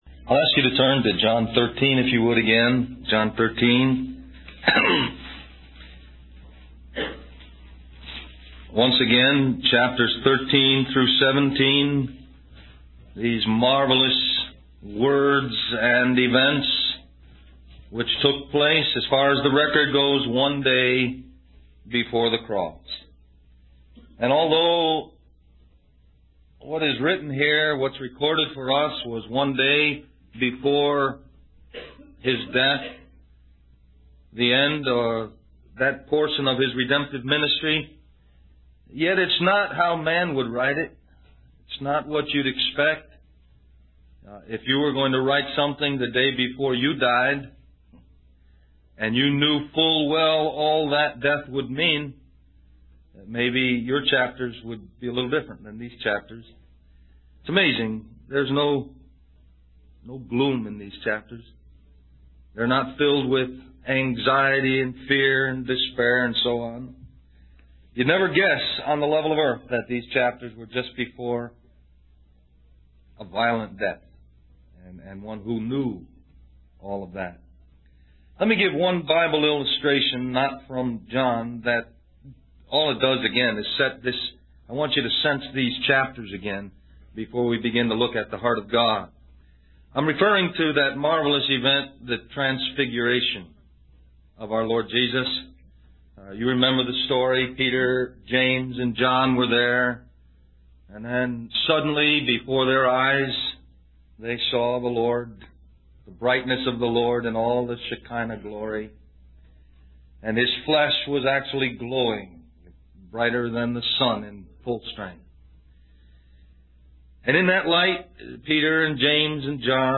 Del Mar Va Mens Retreat 1998 List